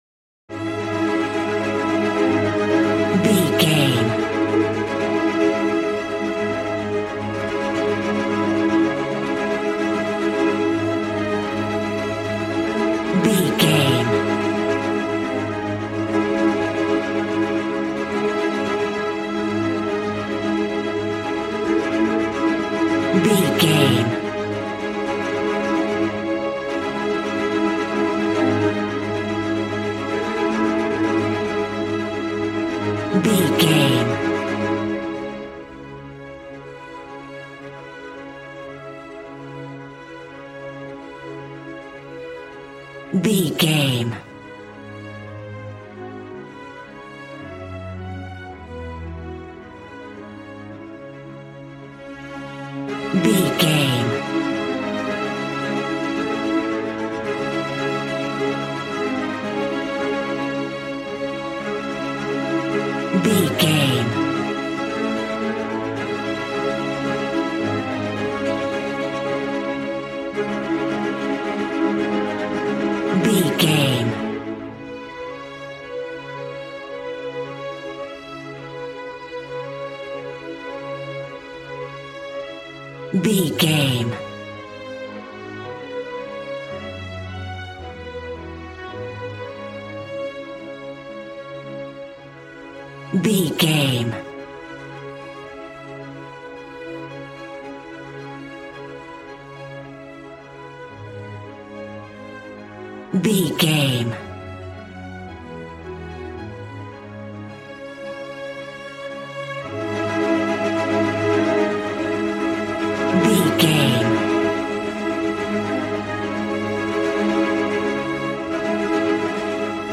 Regal and romantic, a classy piece of classical music.
Ionian/Major
regal
strings
brass